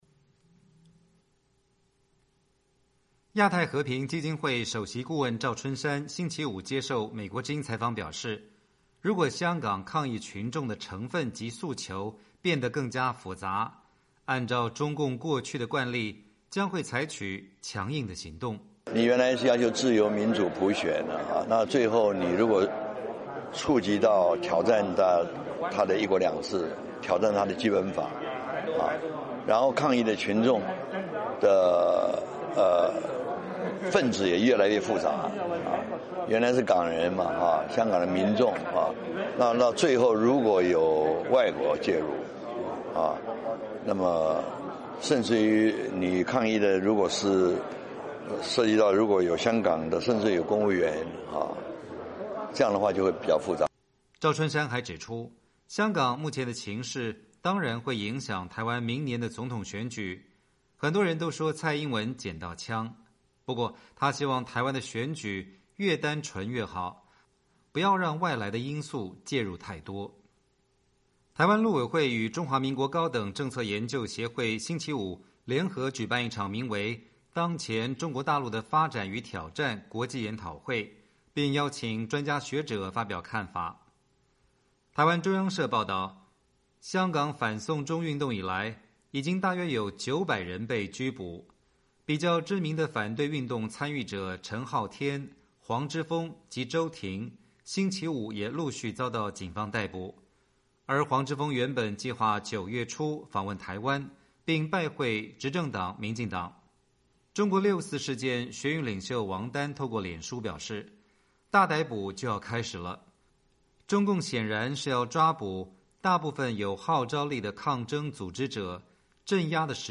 台湾陆委会与中华民国高等政策研究协会星期五联合举办一场名为“当前中国大陆的发展与挑战”国际研讨会，并邀请专家学者发表看法。